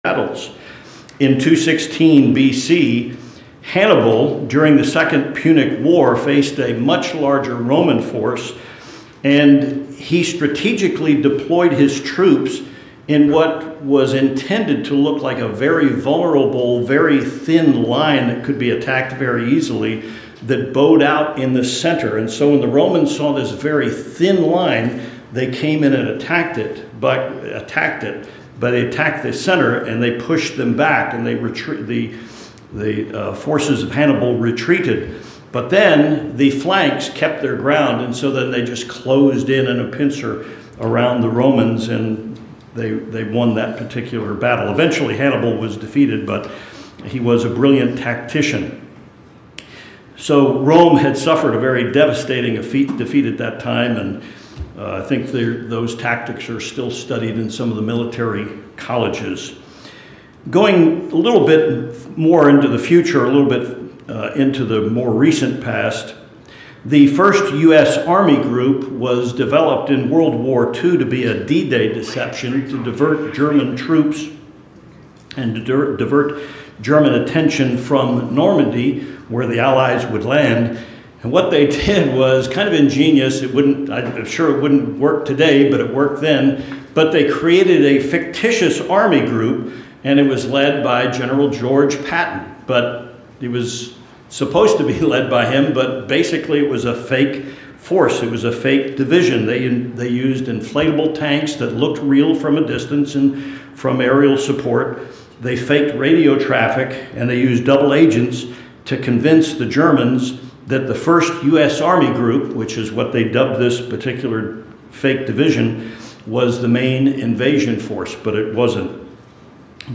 This is the final sermon of three in a series of messages designed to help Christians fight the spiritual battle we are in. This sermon examines the tactics and strategies that the devil uses in warfare.